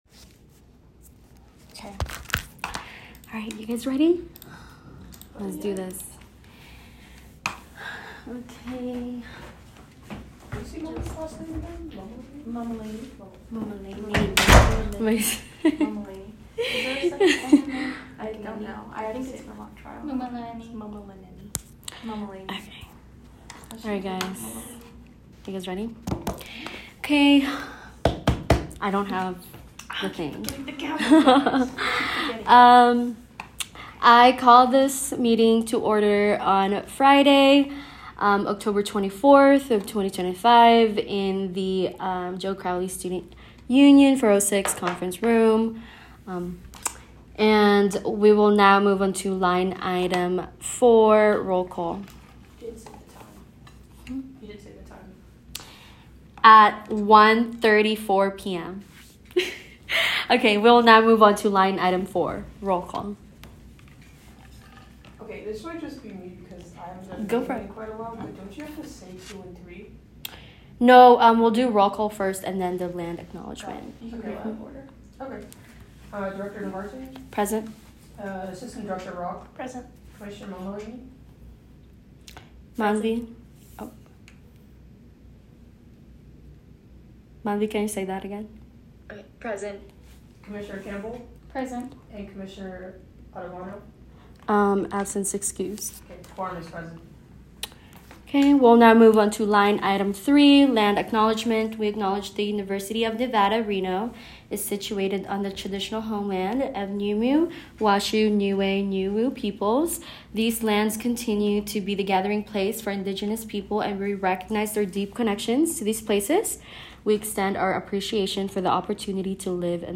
Location : Joe Crowley Student Union 406 Conference Room
10-24-meeting-idea.m4a